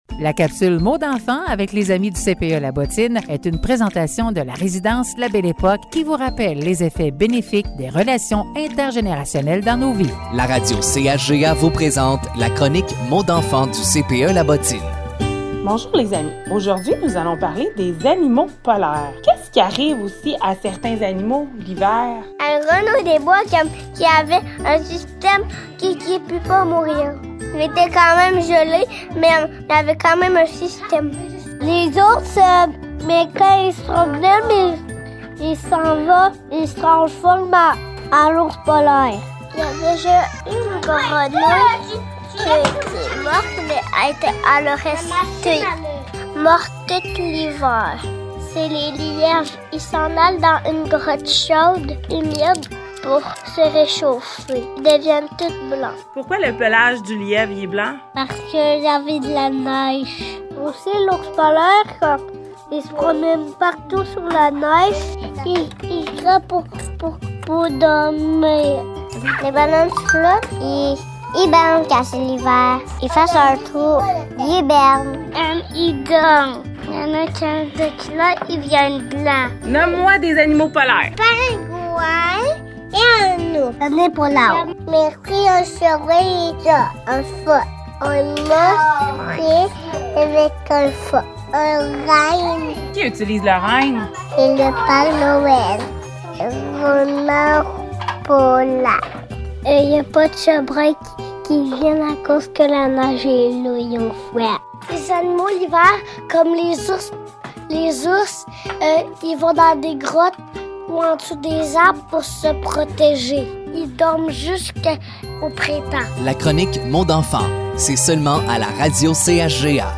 Les enfants du CPE La bottine nous parlent des animaux qui aiment l'hiver.